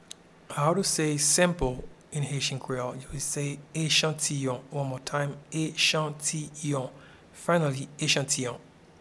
Pronunciation and Transcript:
Sample-in-Haitian-Creole-Echantiyon.mp3